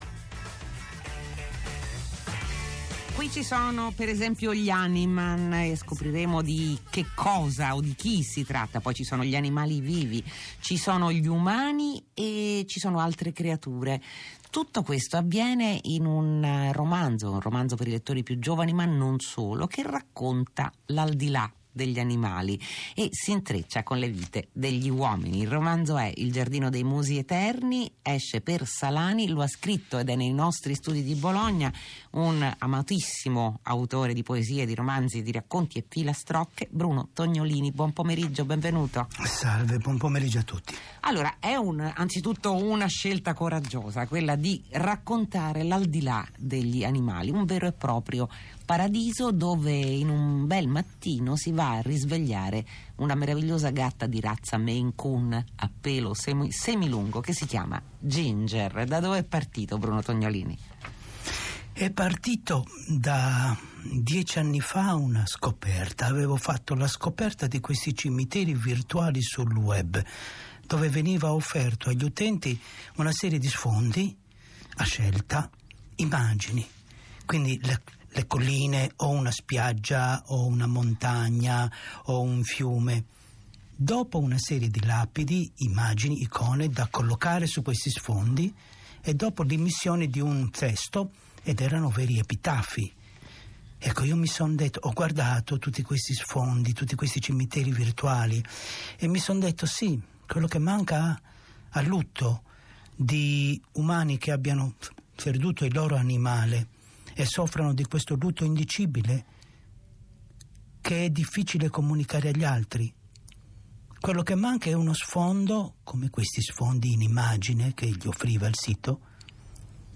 L'intervista di Loredana Lipperini , Fahrenheit, Radio Tre, 07/03/2017